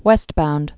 (wĕstbound)